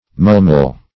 mulmul - definition of mulmul - synonyms, pronunciation, spelling from Free Dictionary Search Result for " mulmul" : The Collaborative International Dictionary of English v.0.48: Mulmul \Mul"mul\, n. A fine, soft muslin; mull.